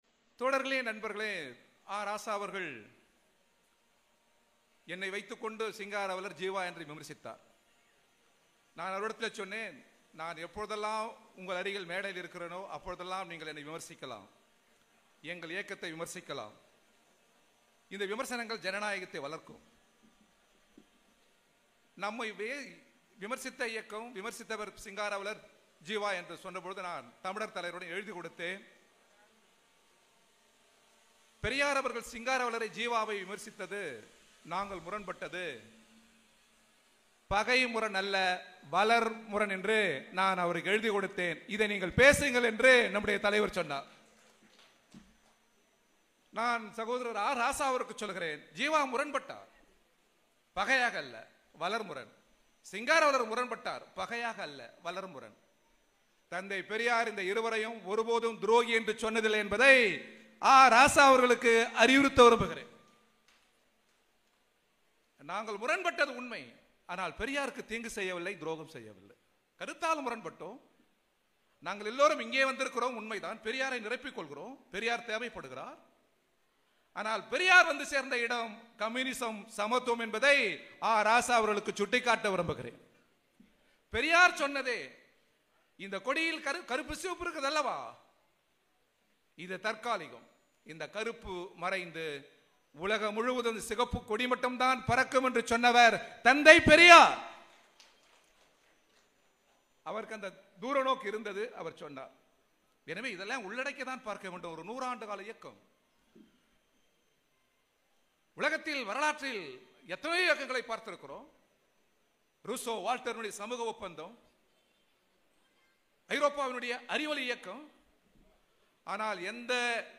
செங்கல்பட்டு மாவட்டம் மறைமலை நகரில் 4.10.2025 அன்று திராவிடர் கழகம் சார்பில் சுயமரியாதை இயக்க நூற்றாண்டு நிறைவு விழா மாநாடு நடைபெற்றது.